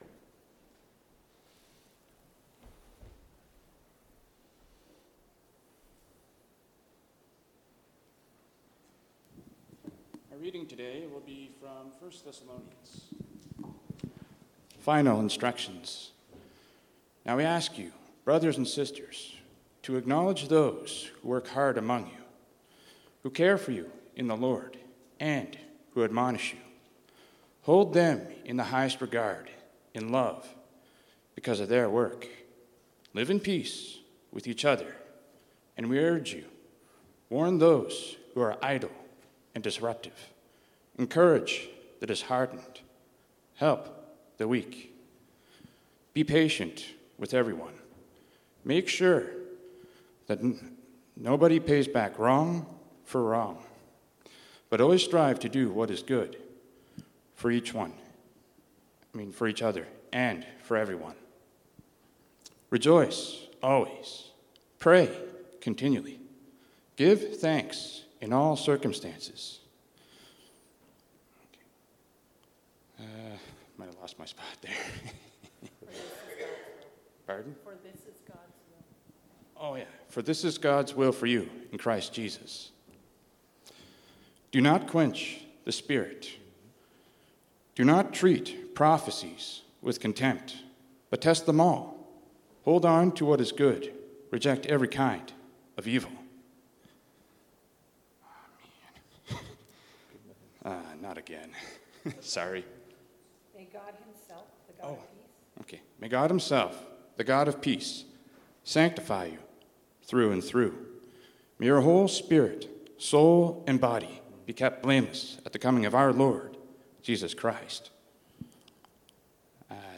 Sermon Messages